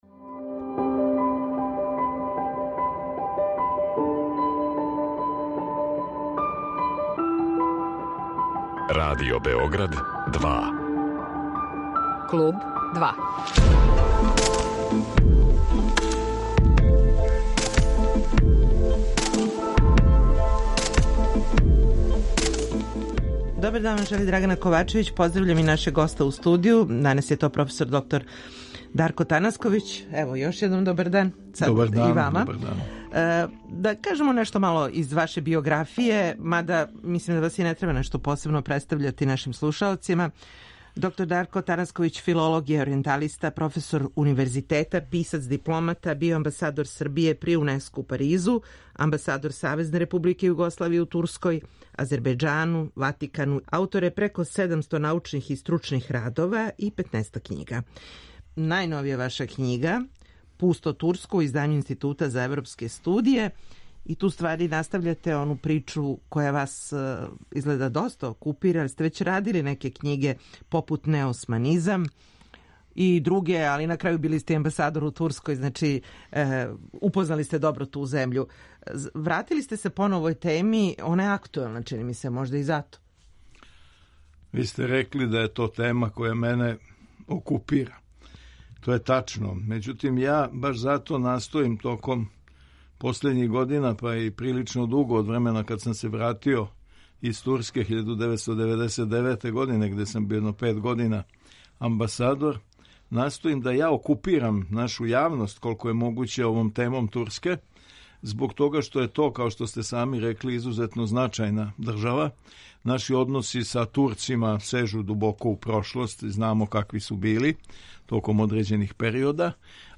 Гост емисије Клуб 2 је проф. др Дарко Танасковић, филолог, оријенталиста, преводилац, писац и дипломата.